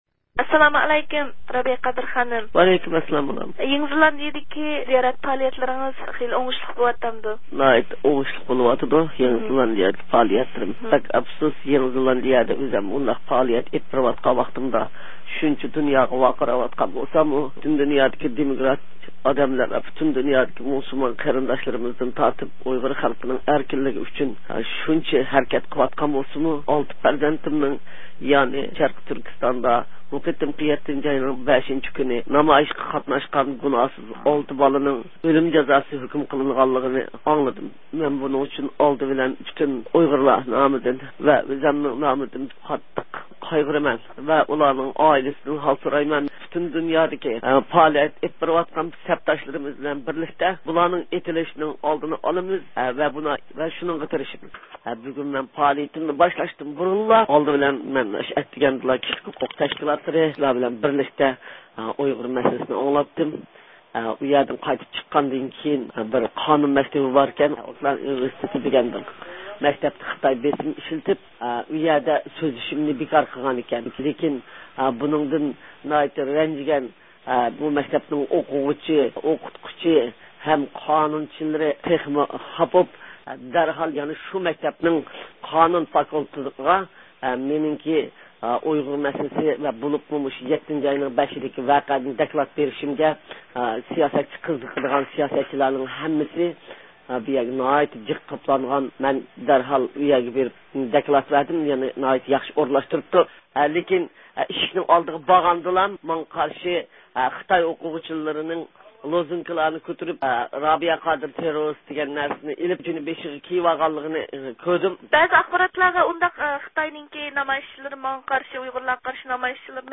يېڭى زېلاندىيىدە پائالىيەتلىرىنى داۋاملاشتۇرۇۋاتقان ئۇيغۇر مىللىي ھەرىكىتى رەھبىرى رابىيە قادىر خانىم، بۈگۈنكى تېلېفون زىيارىتىمىز جەريانىدا، ئۆزىنىڭ سەيشەنبىدىكى پائالىيەتلىرىنى قىسقىچە تونۇشتۇردى ھەمدە كېيىنكى پائالىيەت پىلانلىرى ھەققىدە مەلۇمات بەردى.
بۇ ھەقتە رابىيە قادىر خانىم بىلەن تېلېفون سۆھبىتى ئېلىپ باردۇق.